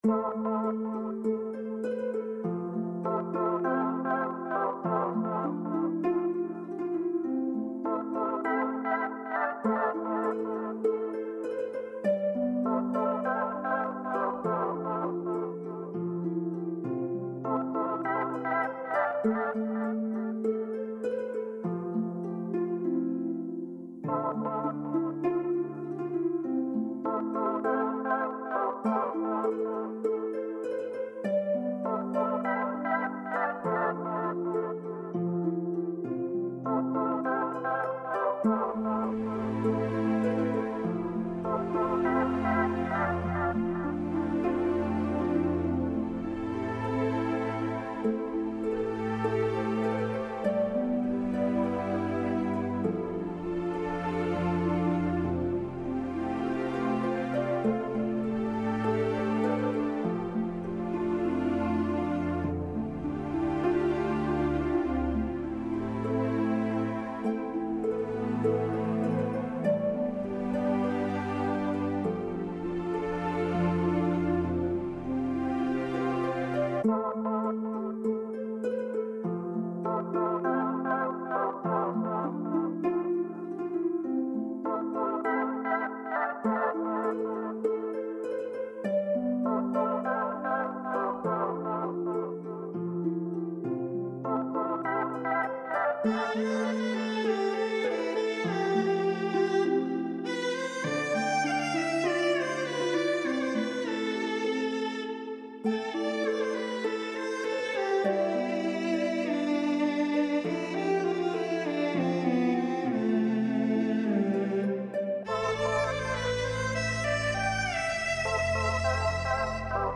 100ambient
100ambient.mp3